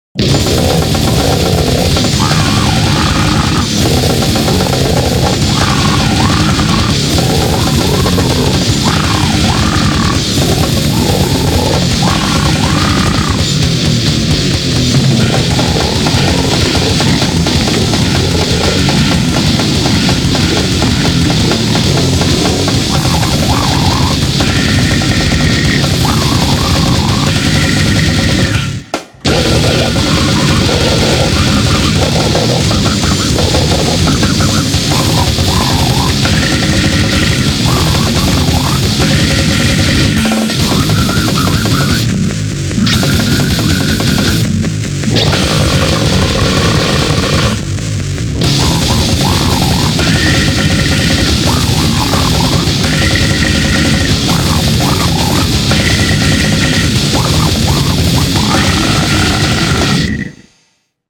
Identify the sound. genre: goregrind